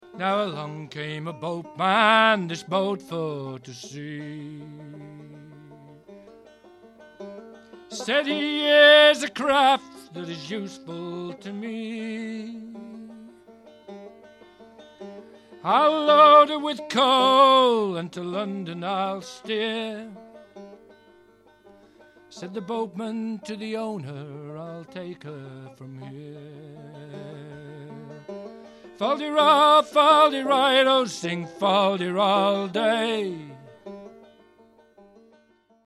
with banjo in hand
Then he gave us an unaccompanied traditional song.
It's all at Ashington Folk Club!